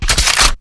generic_switch.wav